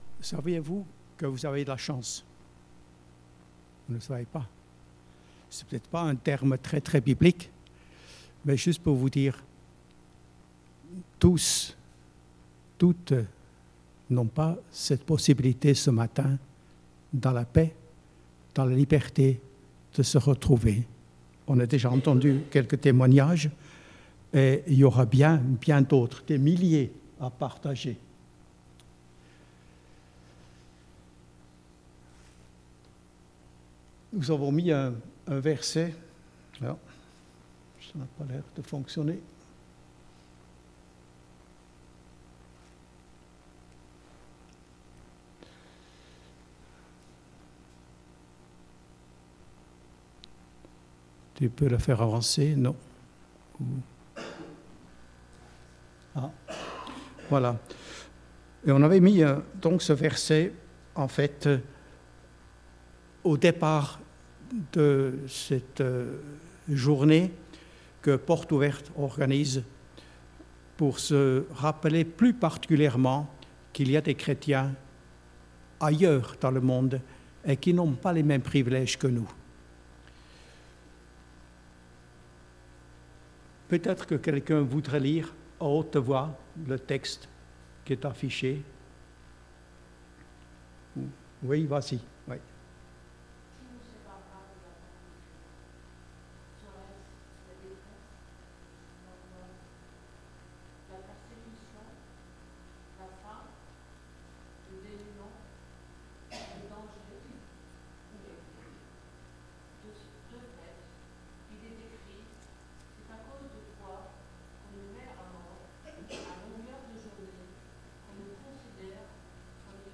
Ecoutez les différents messages de l'église évangélique de Bouxwiller